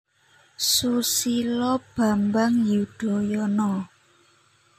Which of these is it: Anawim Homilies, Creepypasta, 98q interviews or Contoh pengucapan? Contoh pengucapan